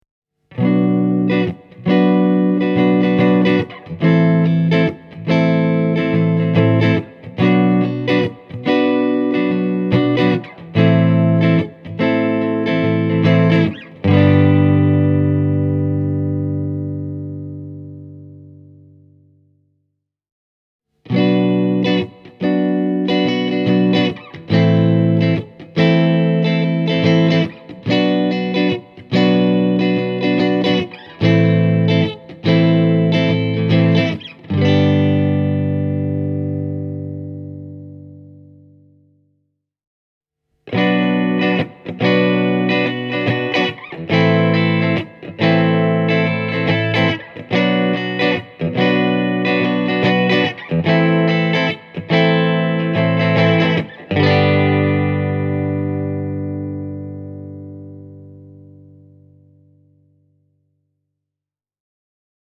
Bluetone Princetonilla kuulostaa – 12-tuumaisen kaiuttimen ansiosta – heti kättelyssä hieman isommalta.
Tältä kuulostaa Fender Telecaster, kun Bluetonessa on puhtaat asetukset:
bluetone-princeton-reverb-e28093-telecaster-clean.mp3